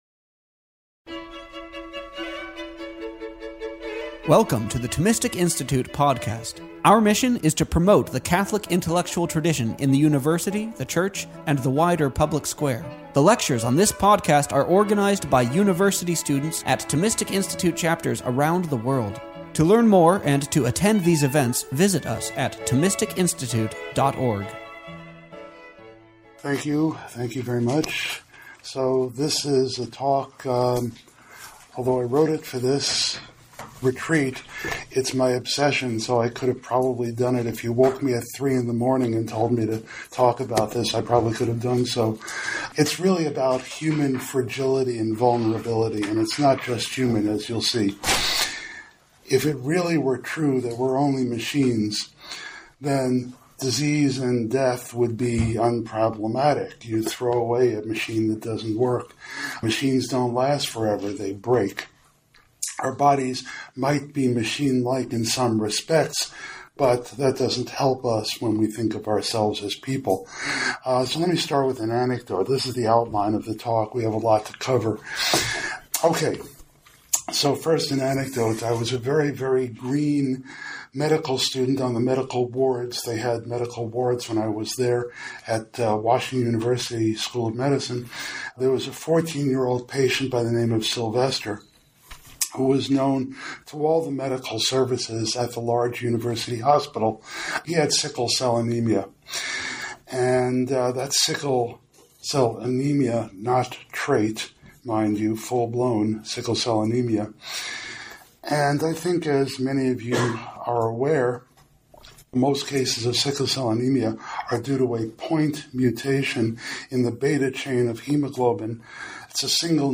The thought of St. Thomas Aquinas, the Universal Doctor of the Church, is our touchstone. The Thomistic Institute Podcast features the lectures and talks from our conferences, campus chapters events, intellectual retreats, livestream events, and much more.